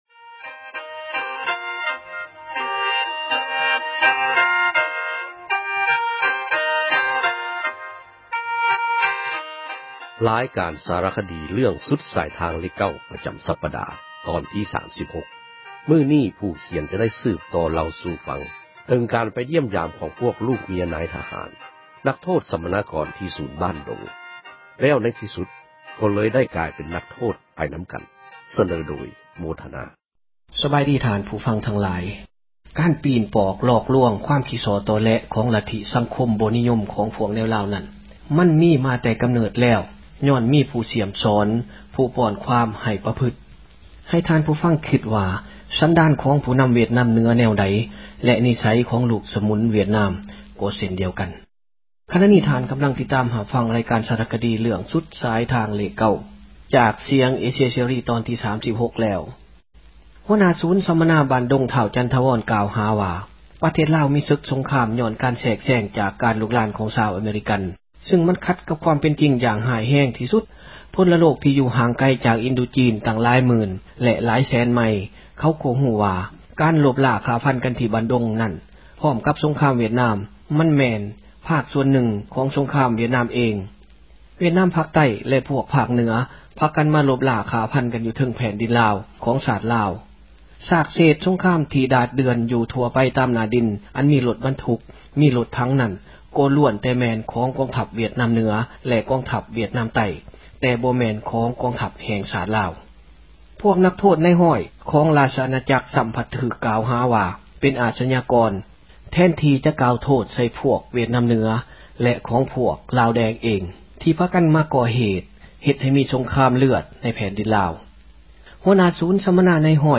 ລາຍການສາຣະຄະດີ ເຣື້ອງ "ສຸດສາຍທາງເລກ 9" ປະຈໍາສັປດາຕອນທີ 36.